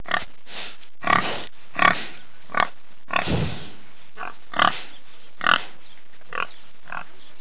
دانلود صدای خوک برای کودکان از ساعد نیوز با لینک مستقیم و کیفیت بالا
جلوه های صوتی